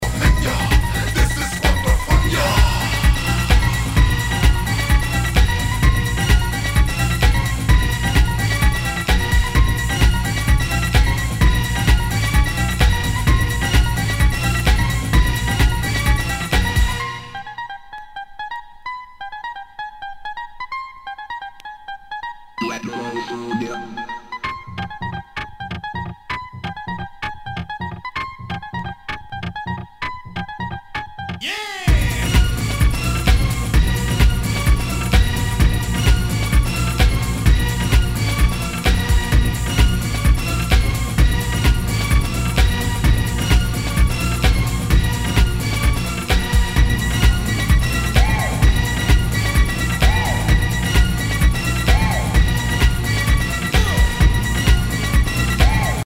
HOUSE/TECHNO/ELECTRO
ナイス！ブレイクビーツ・テクノ・クラシック！